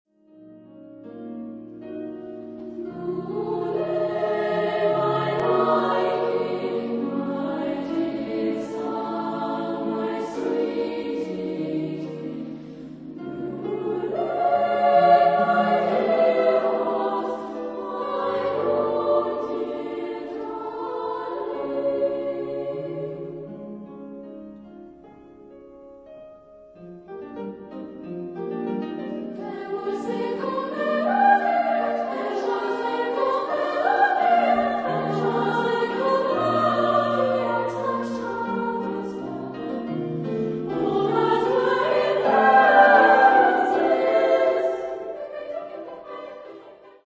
Genre-Stil-Form: Weihnachtslied
Chorgattung: SSA  (3-stimmiger Frauenchor )
Solisten: Soprano (1)  (1 Solist(en))
Instrumentation: Klavier  (1 Instrumentalstimme(n))
Tonart(en): Des-Dur